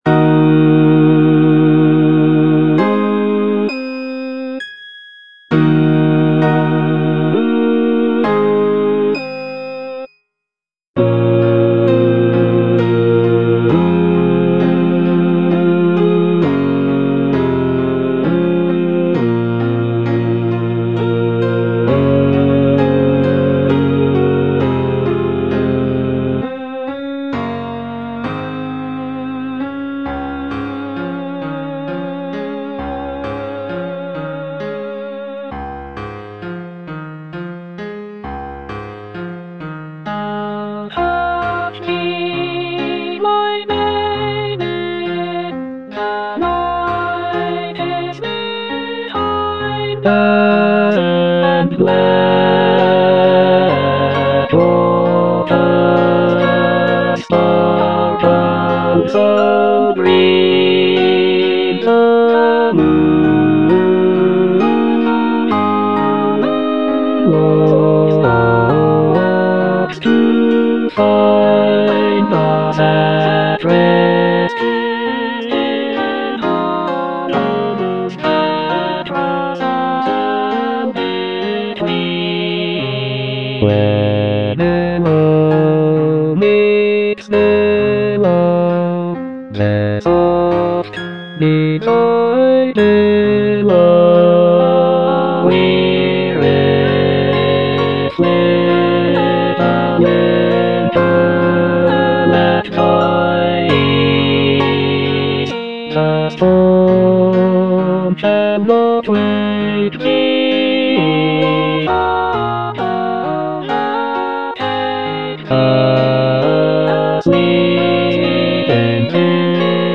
Bass I (Emphasised voice and other voices)